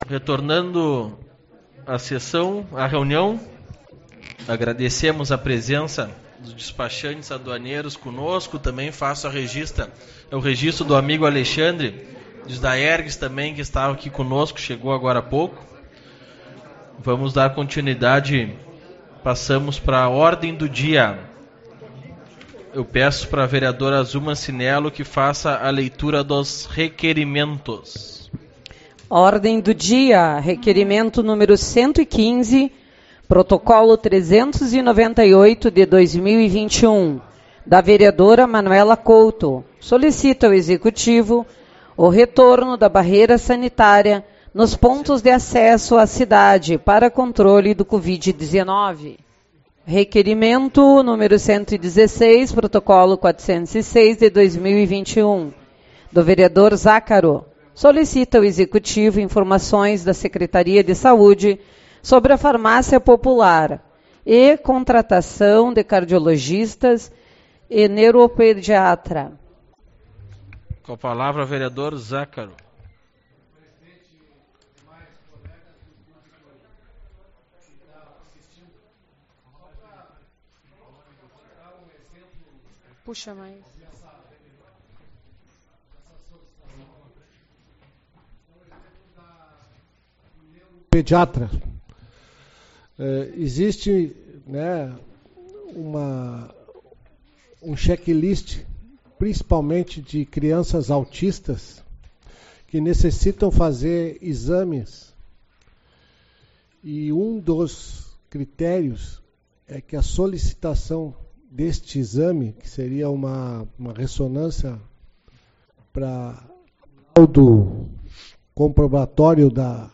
27/04 - Reunião Ordinária